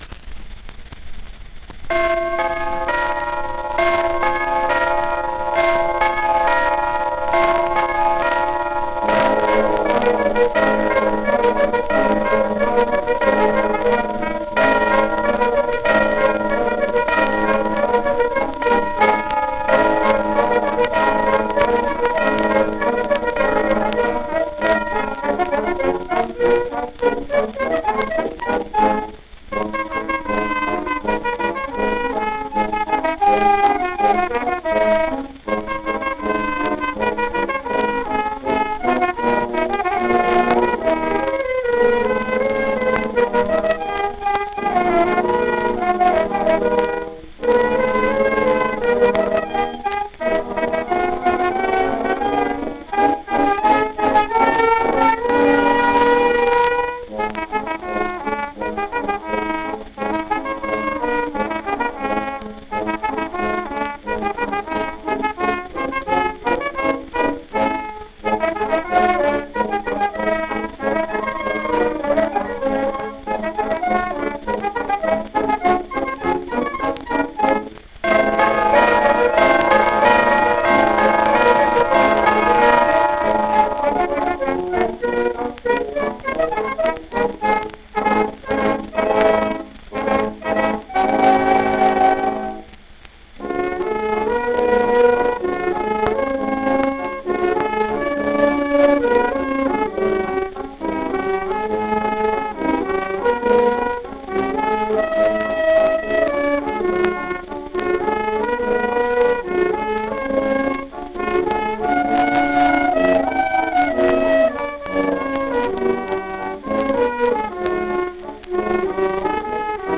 1 disco : 78 rpm